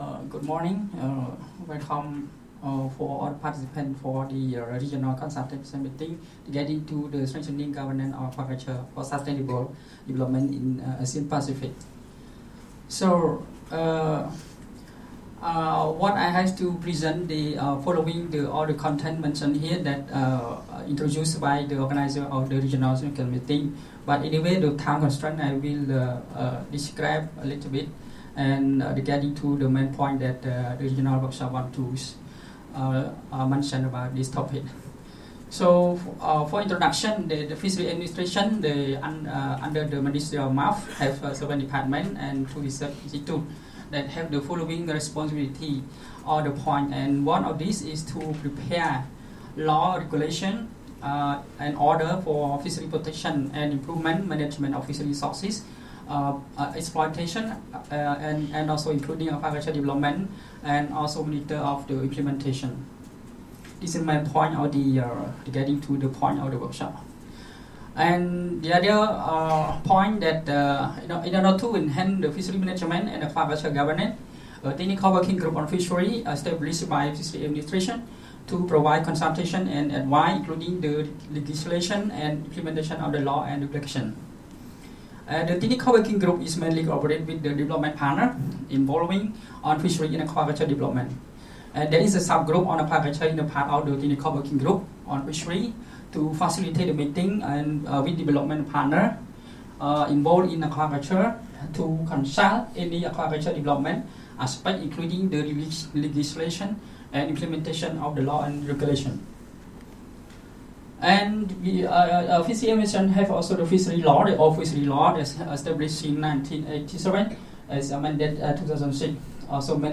Audio recording of presentation delivered at the Consultation on Strengthening Governance of Aquaculture for Sustainable Development in Asia-Pacific, 5-6 November 2019, Bangkok, Thailand.